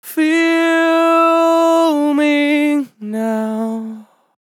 Categories: Vocals Tags: dry, english, Feel, LOFI VIBES, LYRICS, male, me, now, sample
MAN-LYRICS-FILLS-120bpm-Am-13.wav